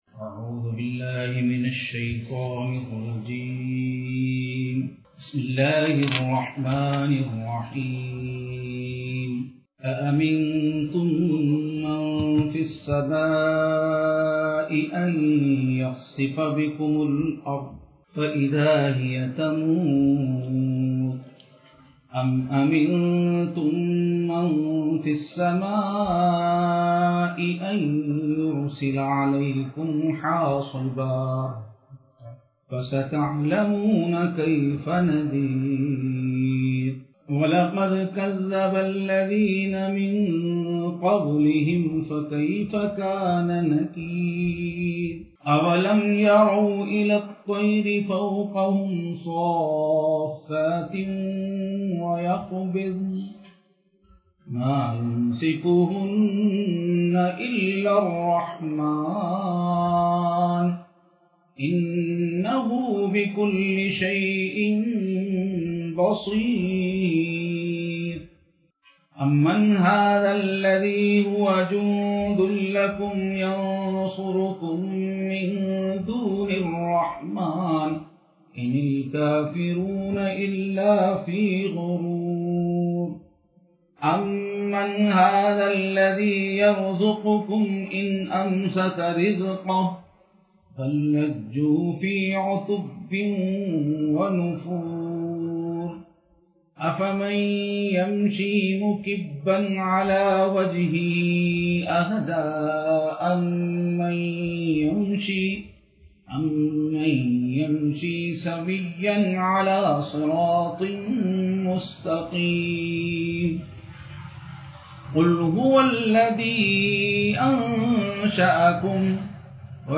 Live Online Bayan